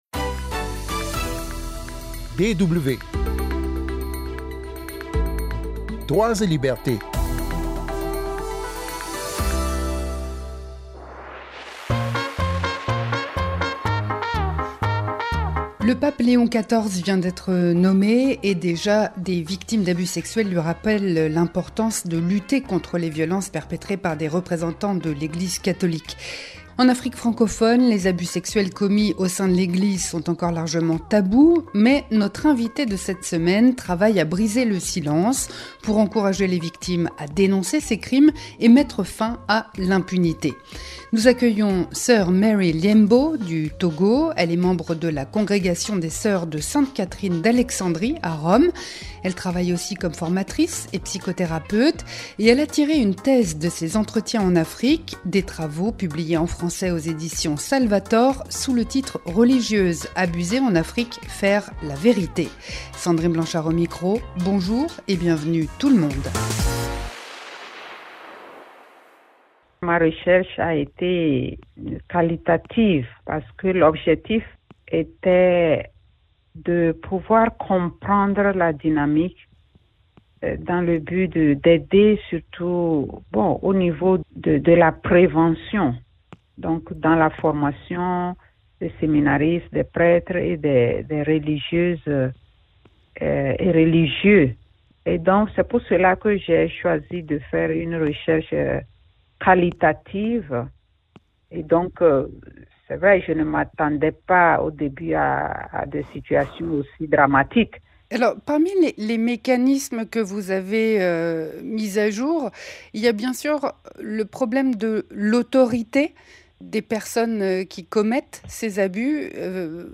Chaque semaine, Droits et Libertés propose un éclairage sur une atteinte à la dignité humaine, mais aussi sur le combat, mené sous toutes les latitudes, par des défenseurs des droits de l’homme. De la peine de mort à la discrimination des minorités, de la répression du droit syndical à la persistance de pratiques traditionnelles dégradantes – ce podcast vous propose des reportages et des interviews qui illustrent l’universalité de tous droits humains : politiques, civils, économiques, sociaux et